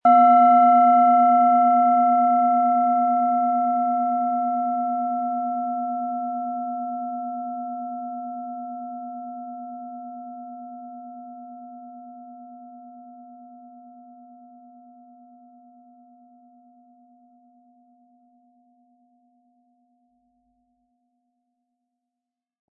Planetenton 1
Planetenschale® Liebevoll sein & Fantasie haben mit Delfin-Ton, Ø 12,3 cm inkl. Klöppel
Die Planetenklangschale Delfin ist von Hand gefertigt worden.
Im Audio-Player - Jetzt reinhören hören Sie genau den Original-Klang der angebotenen Schale. Wir haben versucht den Ton so authentisch wie machbar aufzunehmen, damit Sie gut wahrnehmen können, wie die Klangschale klingen wird.